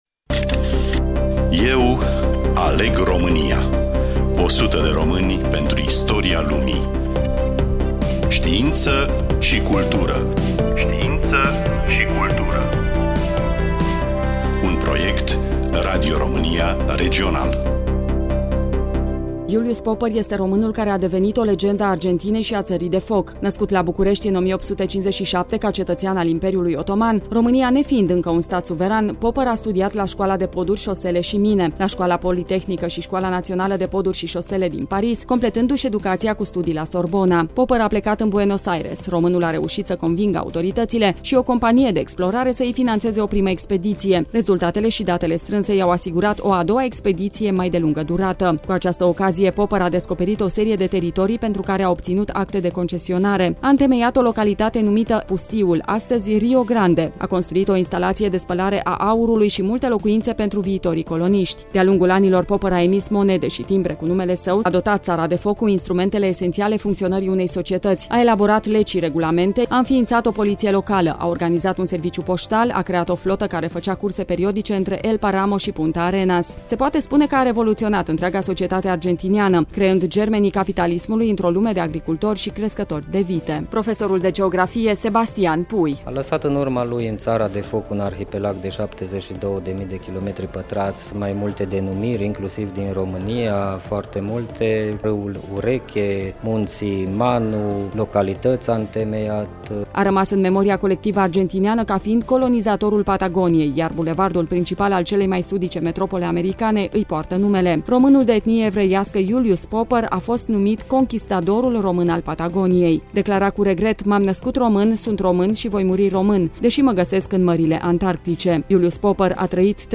Studioul: Radio România Tg.Mureş